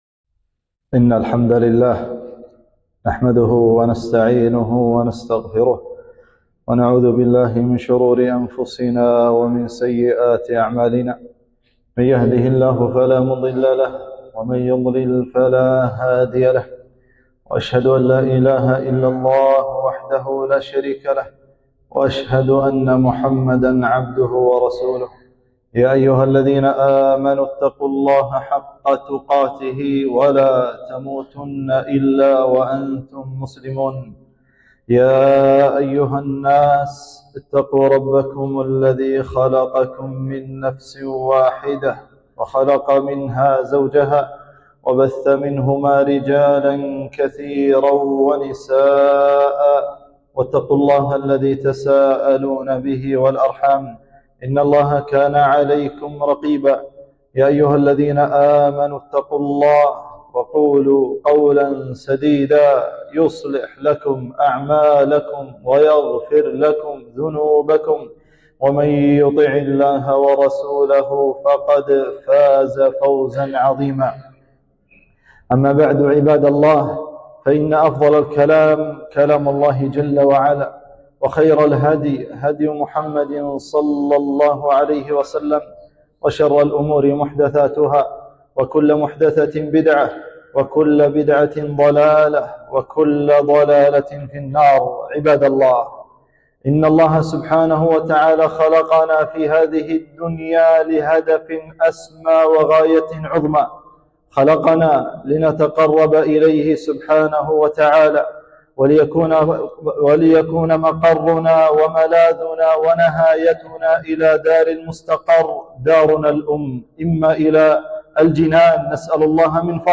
خطبة - خطر اللسان